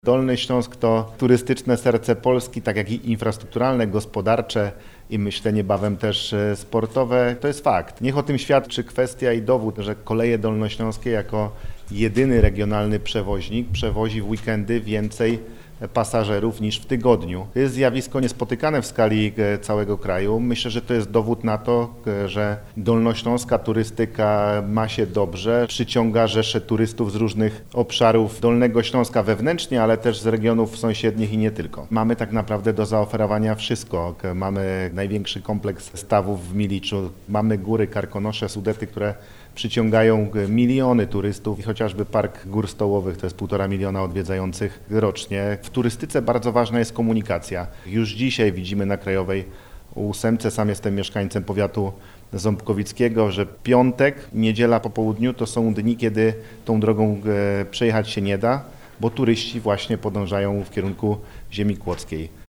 Dolny Śląsk jest infrastrukturalnym i turystycznym sercem Polski, dodaje marszałek Województwa Dolnośląskiego, Paweł Gancarz. Po powodzi z września ubiegłego roku gros środków przeznaczanych jest na odbudowę tego sektora.